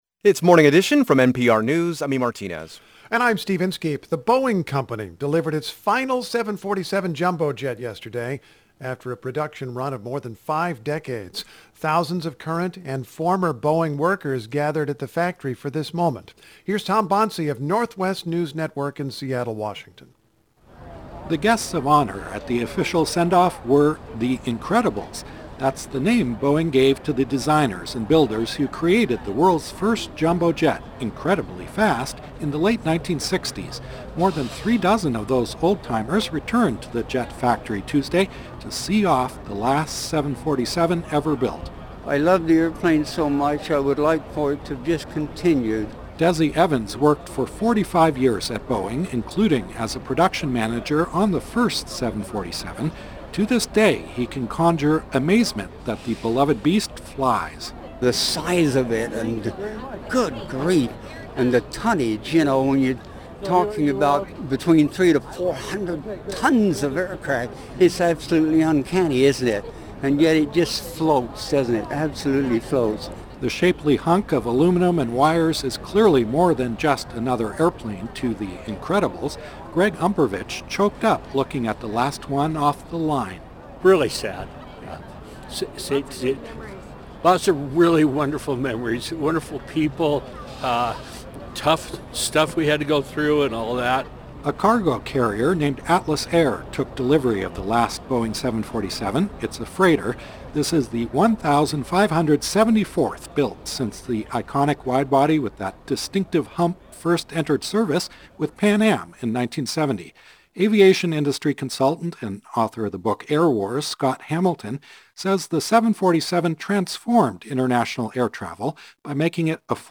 The hours-long celebration for the so-called “Queen of the Skies” took place in the giant building that once housed the 747 production line.